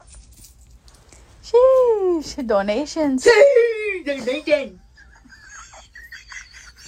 SHEESH Donations meme soundboard clip with excited, hype tone and funny, energetic reaction vibe.